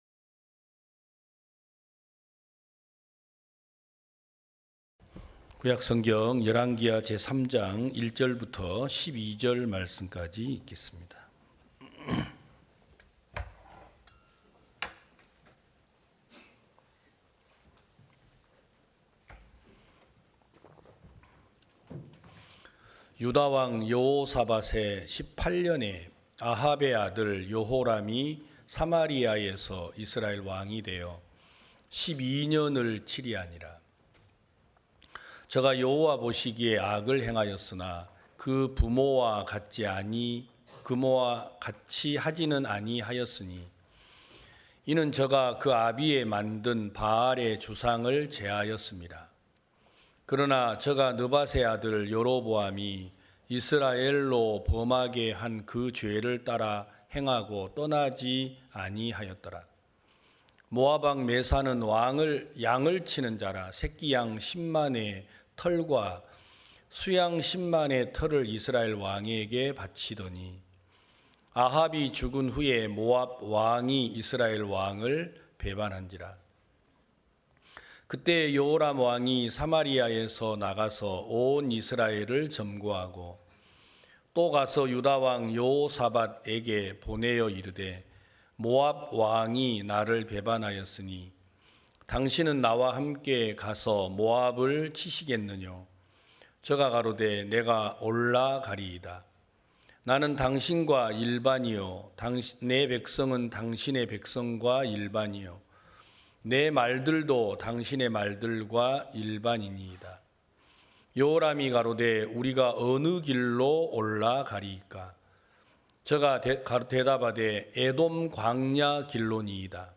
2023년 5월 14일 기쁜소식부산대연교회 주일오전예배
성도들이 모두 교회에 모여 말씀을 듣는 주일 예배의 설교는, 한 주간 우리 마음을 채웠던 생각을 내려두고 하나님의 말씀으로 가득 채우는 시간입니다.